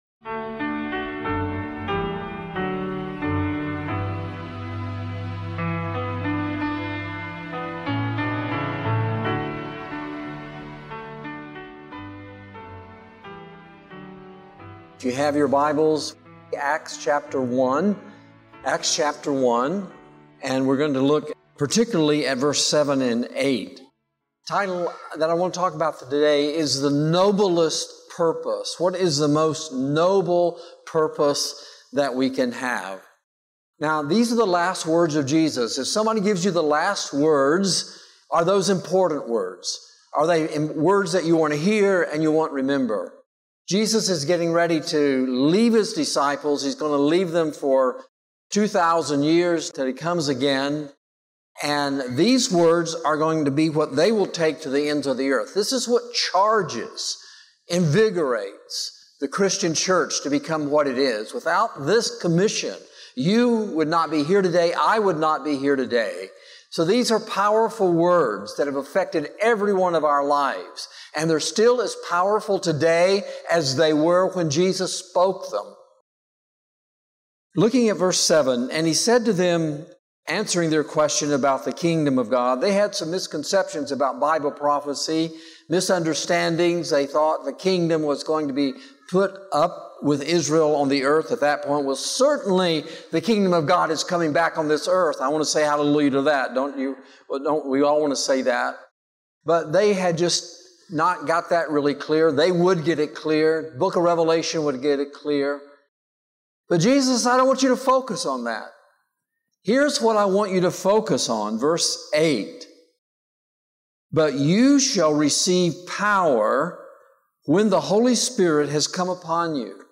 This sermon calls believers back to Jesus’ final commission, reminding us that the church’s life, power, and purpose flow from the Holy Spirit and Christlike love. With a renewed sense of urgency, it challenges every believer to engage in mission through prayer, personal witness, and active participation in God’s harvest.